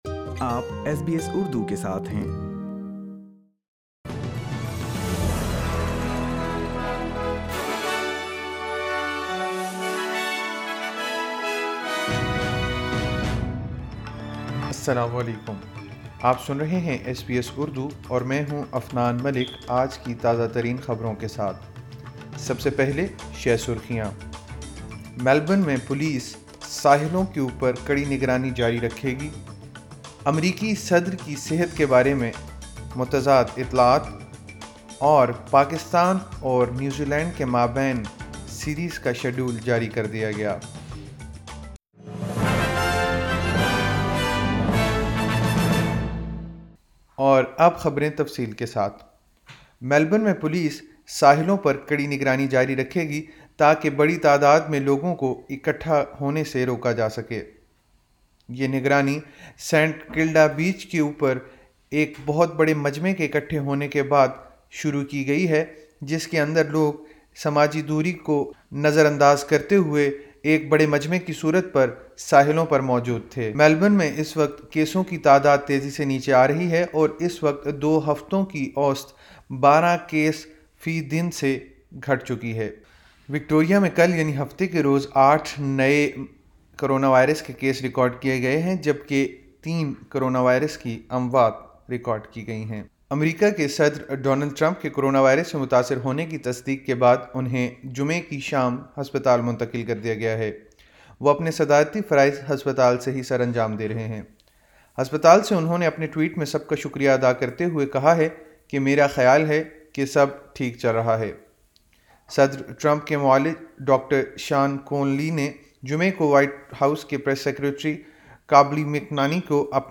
ایس بی ایس اردو خبریں 04 اکتوبر 2020